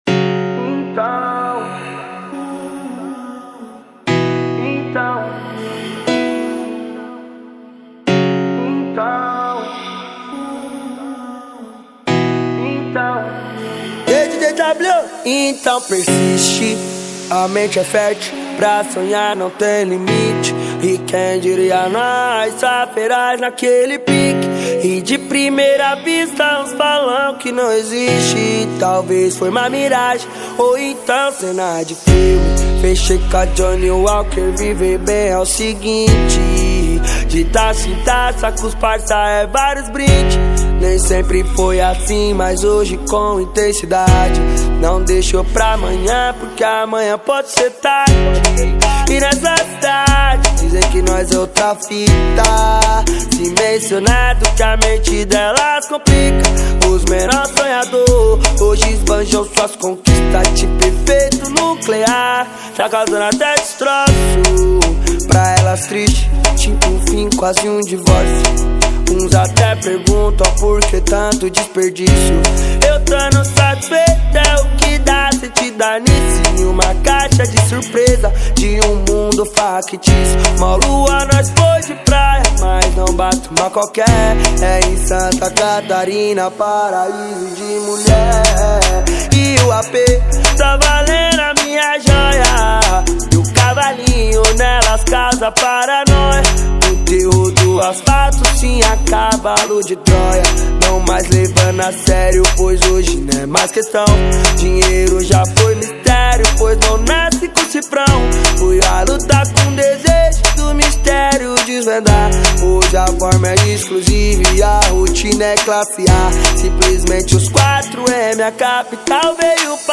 2024-06-05 13:03:30 Gênero: MPB Views